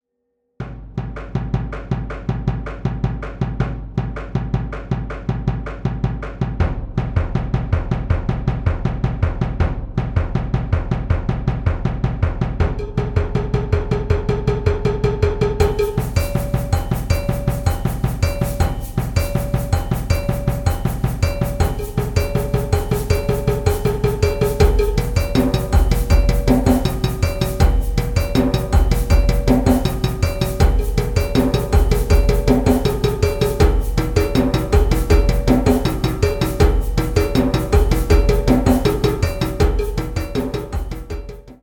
Established in 1993 as a world percussion group